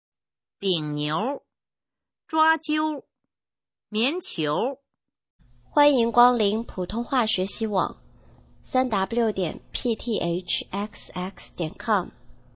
普通话水平测试用儿化词语表示范读音第22部分